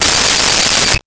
Cell Phone Ring 12
Cell Phone Ring 12.wav